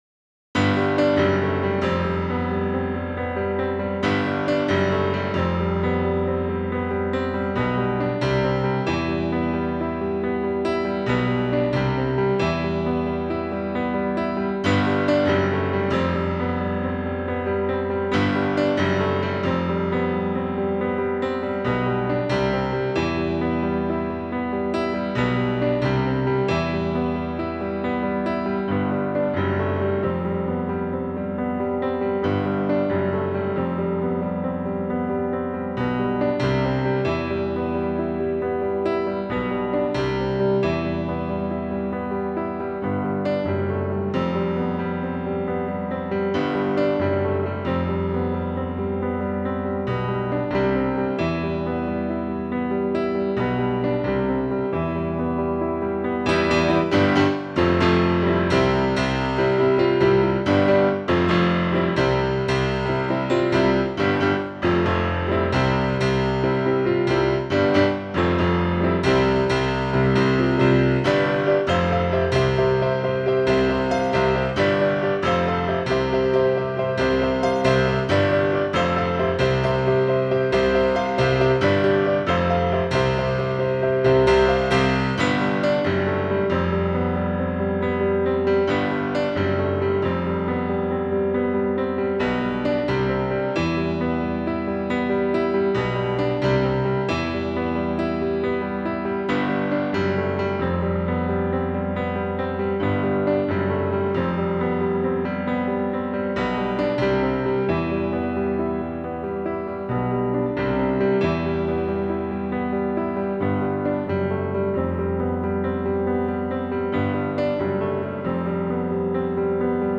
Faith Piano.wav